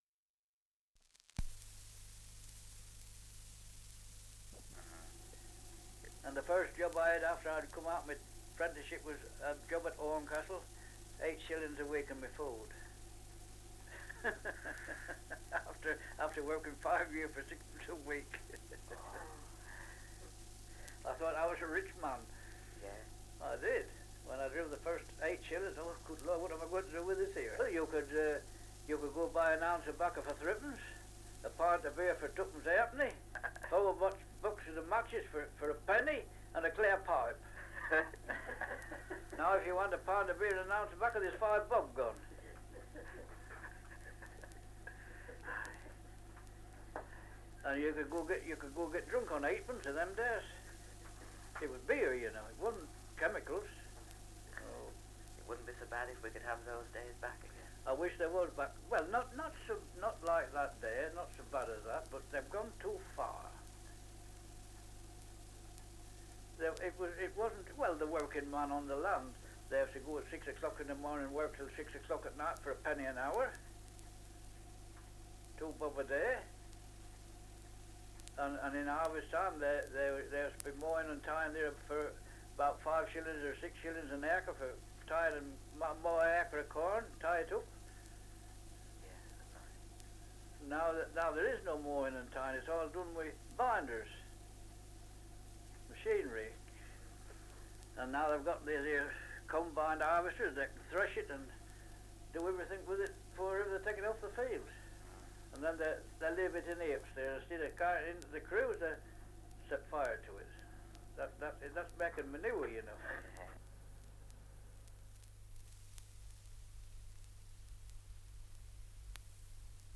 Survey of English Dialects recording in Old Bolingbroke, Lincolnshire
78 r.p.m., cellulose nitrate on aluminium